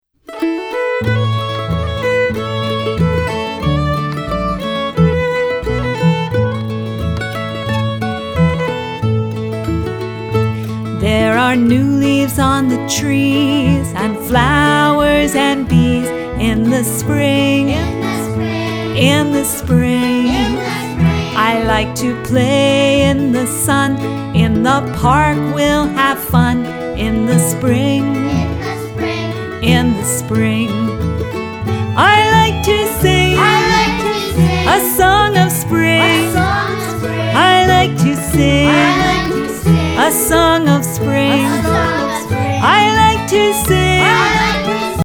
A call and response song about the simple joys of spring!